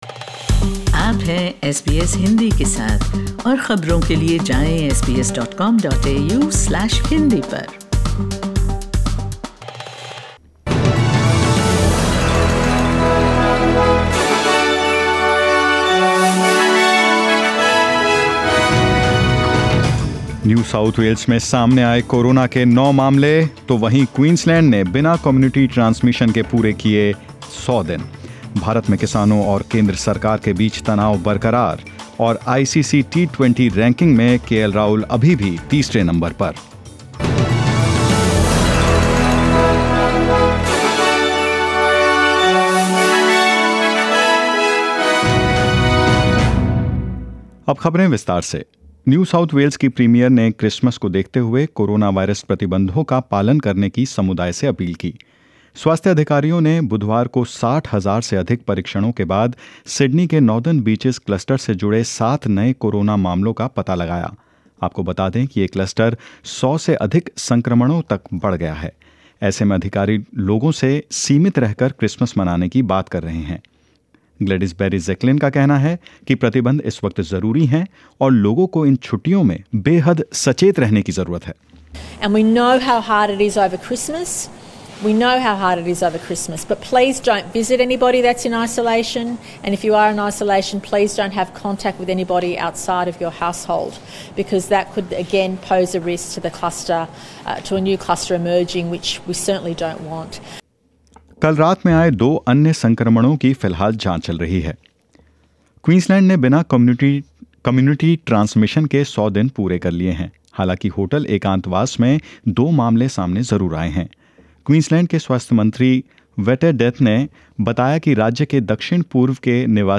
News in Hindi 24 December 2020